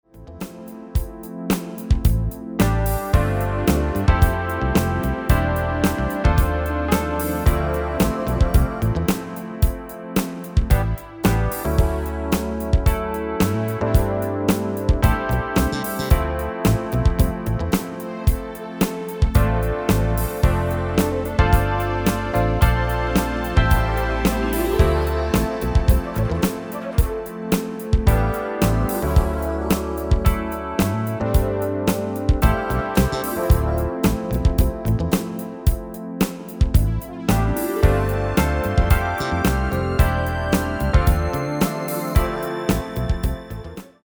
Demo/Koop midifile
Genre: Pop & Rock Internationaal
- GM = General Midi level 1
- Géén vocal harmony tracks